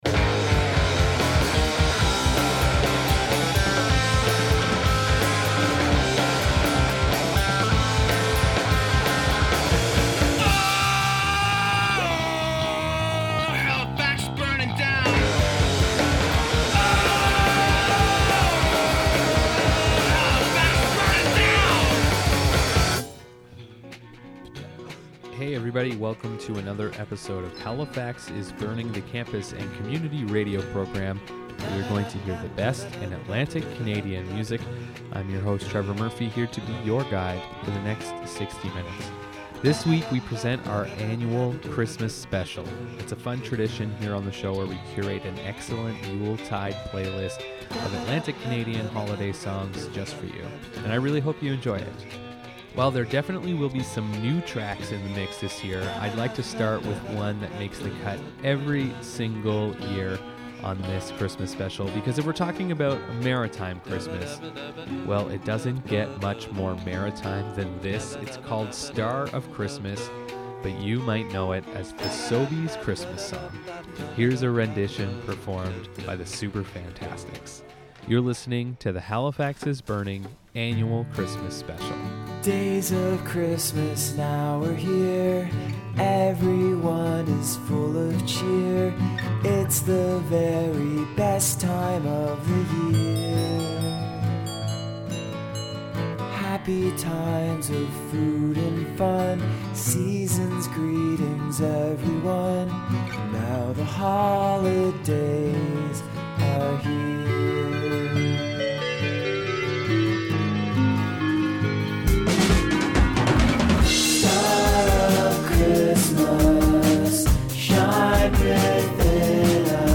The best independent Christmas music in Atlantic Canada